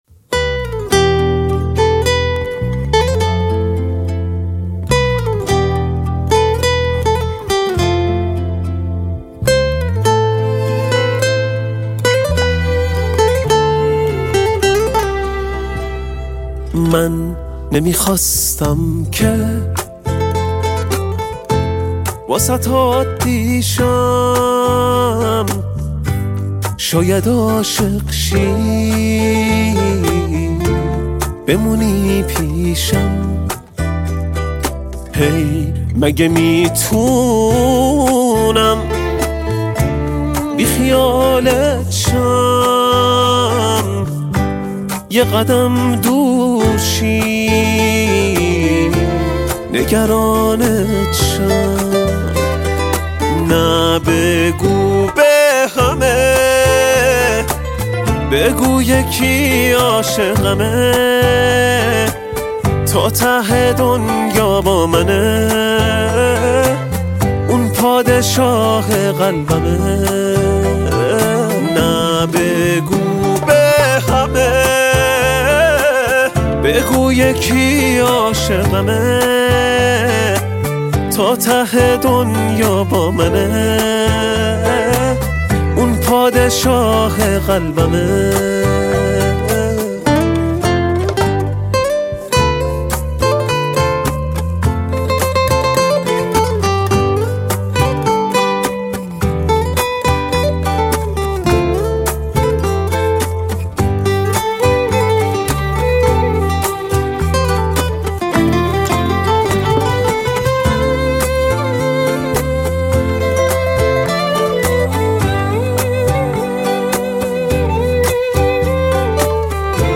(اجرای آنپلاگد)